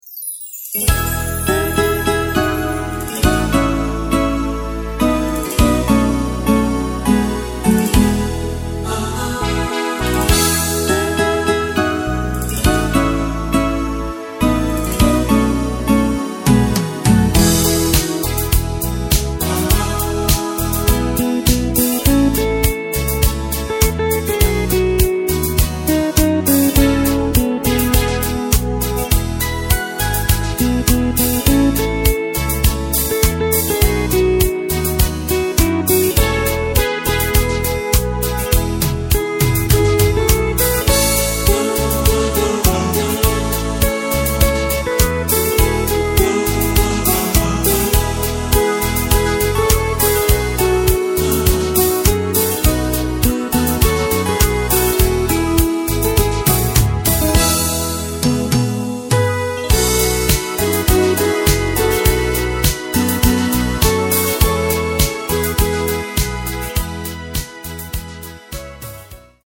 Takt:          4/4
Tempo:         102.00
Tonart:            E
Schlager aus dem Jahr 1981!